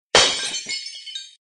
ガラスが割れた時の衝撃音。